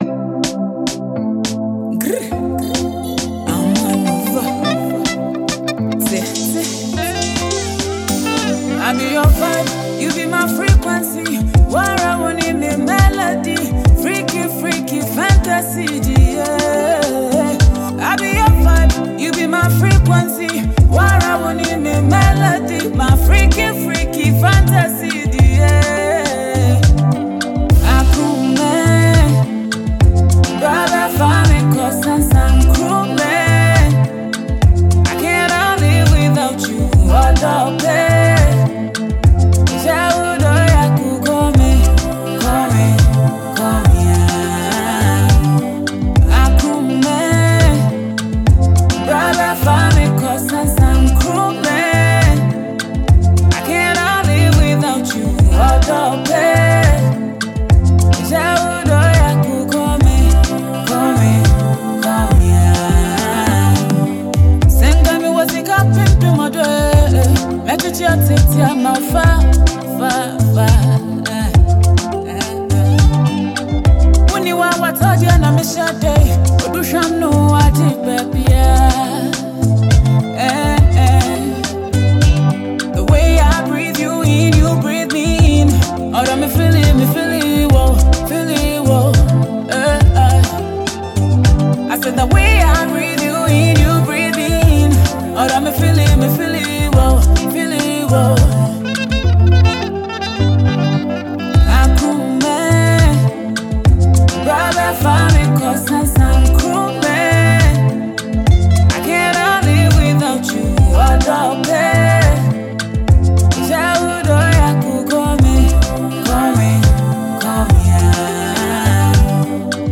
a midtempo tune